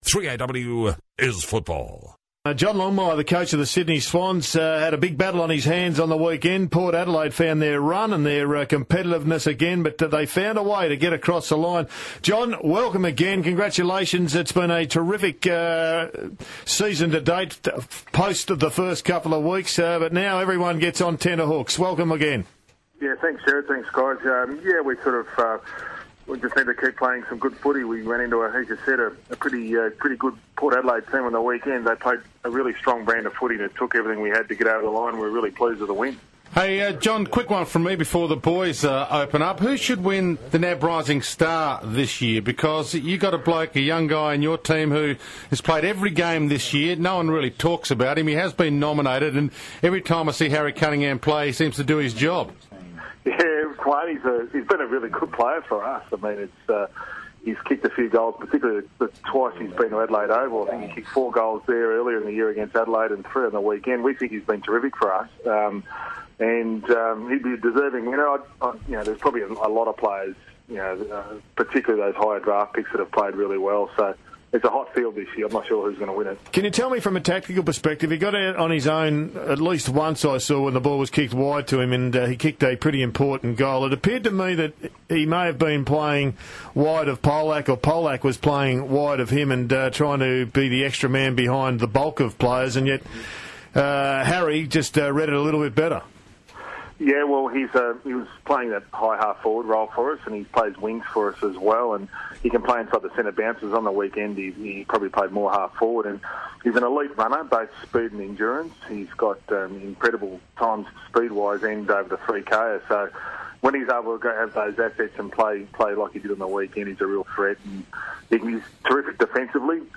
Sydney Swans coach John Longmire appeared on 3AW on Monday August 11, 2014